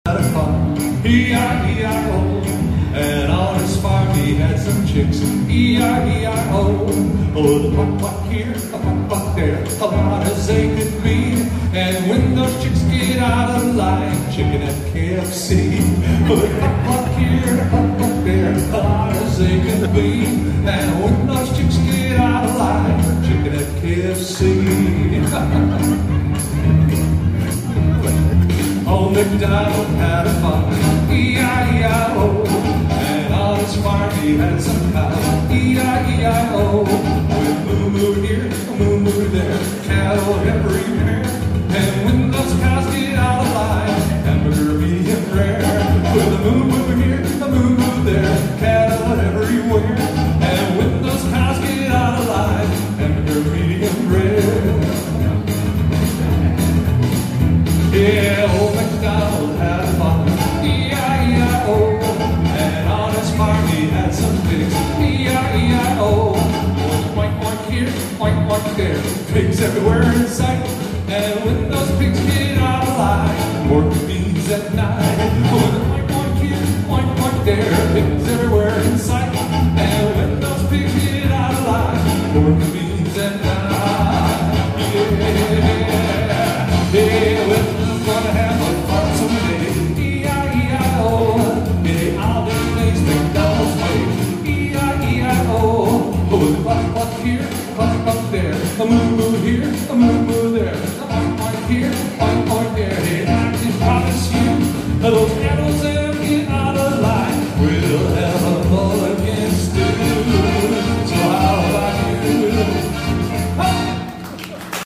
at the cuyahoga county fair